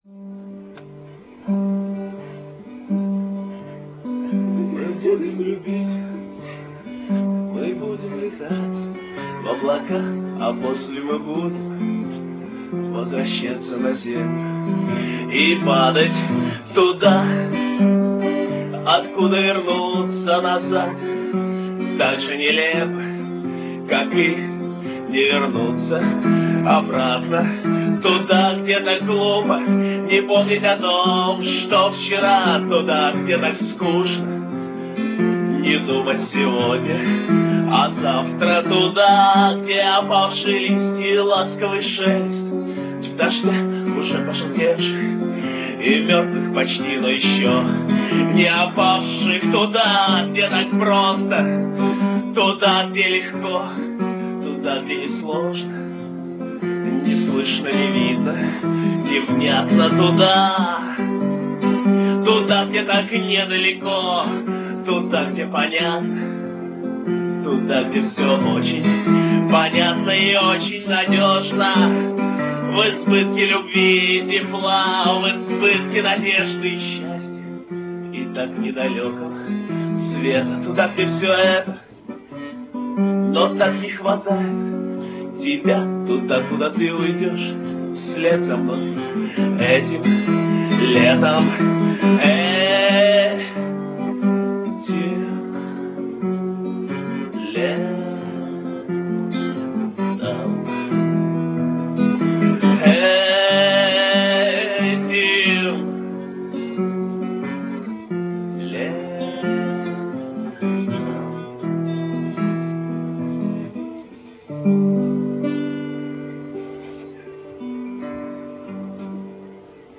Квартирник 26 октября 1999.